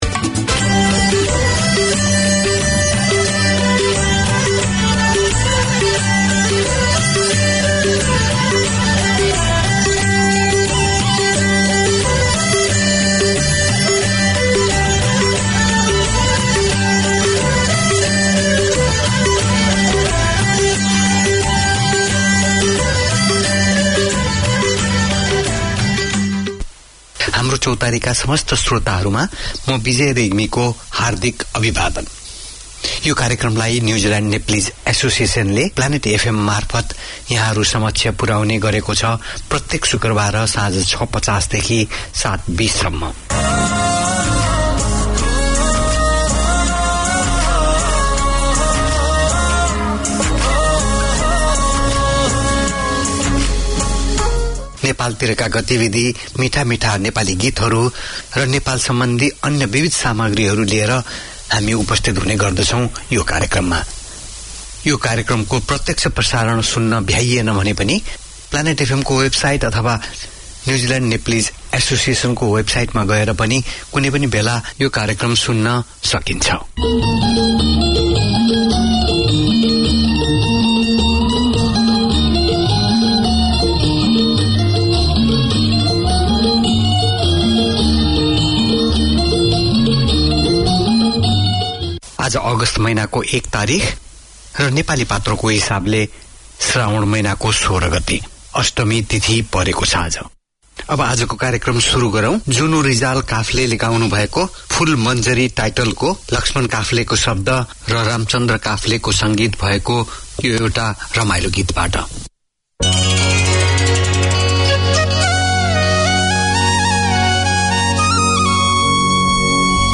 Each week Haamro Chautari offers the chance for the Nepalese community to gather round and share their culture, news of the local community as well as the latest from Nepal. The hosts also present a selection of new music and golden hits. Interviews and updates on community events in Auckland keep the connection with the Nepalese way of life.